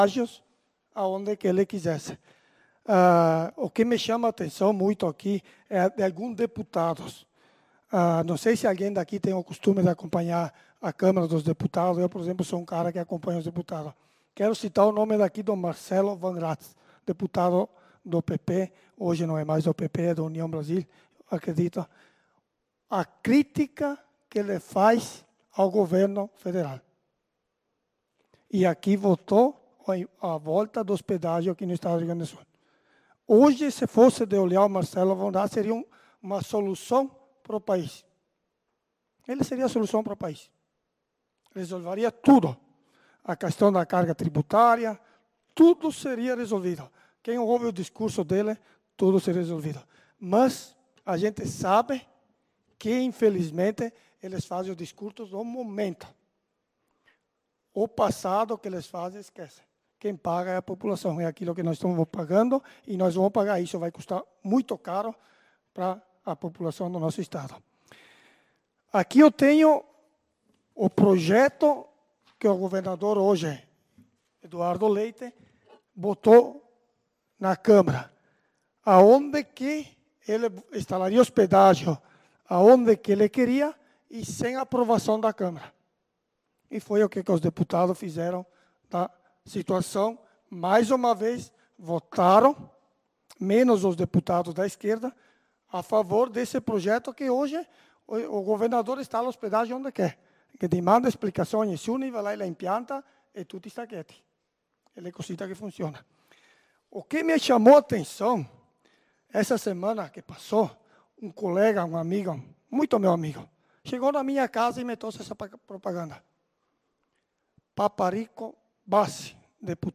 Sessão Ordinária do dia 05/03/2025 - Câmara de Vereadores de Nova Roma do Sul